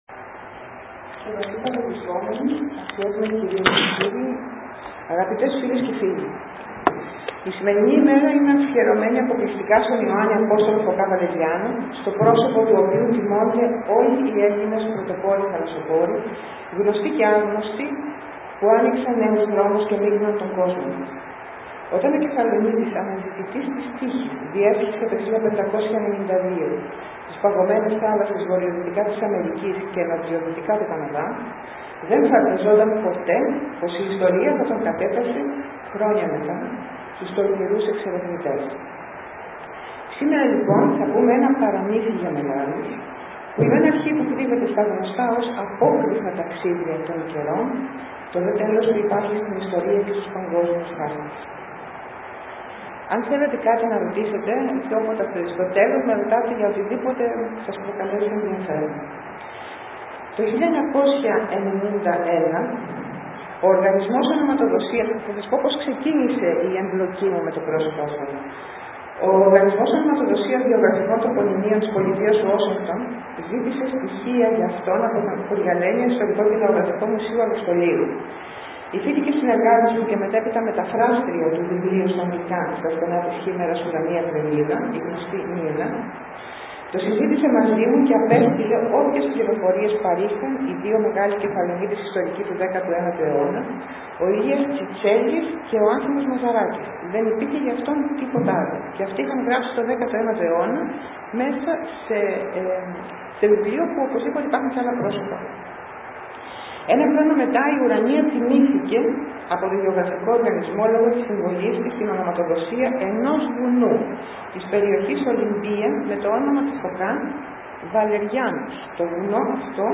Δεύτερη μέρα της 18ης Εκδήλωσης – Συνάντησης Αποδήμων με αφιέρωμα στον μεγάλο θαλασσοπόρο Ιωάννη Φωκά (Juan de Fuca) με αφορμή τα 150 χρόνια του Καναδά ως Ομόσπονδο κράτος, στο Δημοτικό θέατρο Αργοστολιού «Ο Κέφαλος»